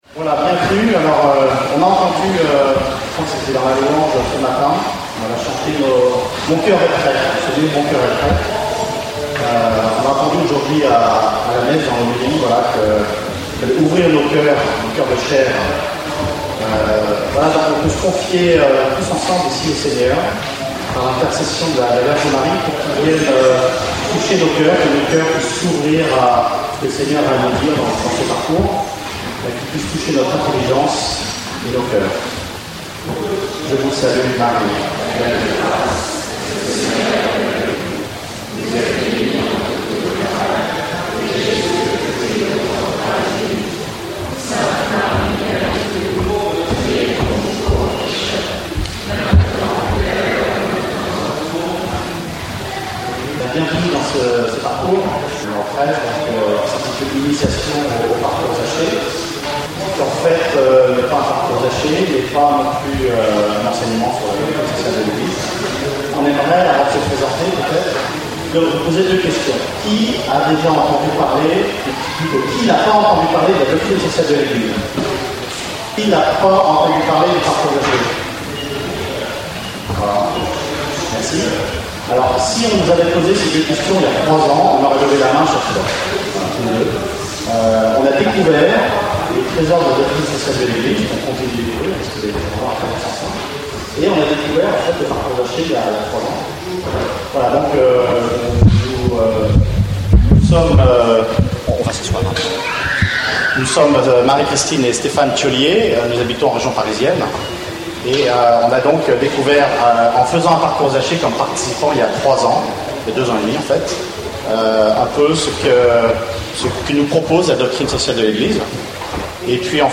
Enseignement
Session famille 3 (du 4 au 9 août 2012)
Format :MP3 64Kbps Mono